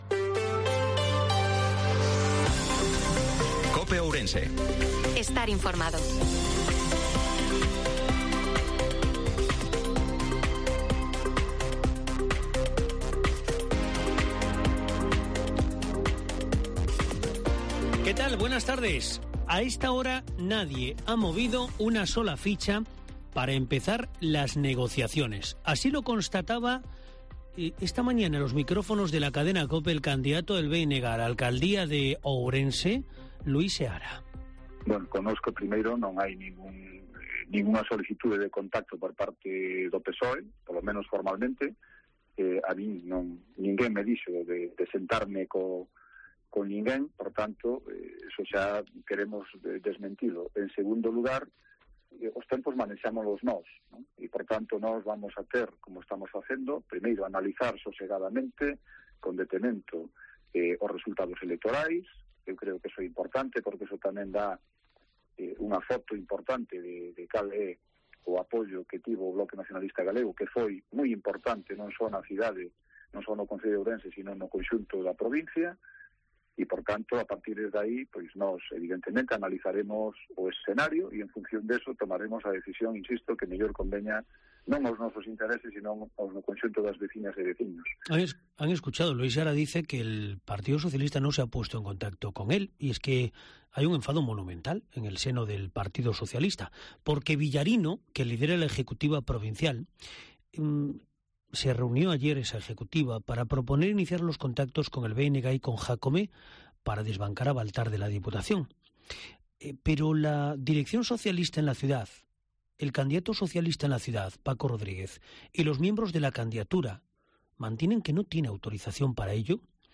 INFORMATIVO MEDIODIA COPE OURENSE-31/05/2023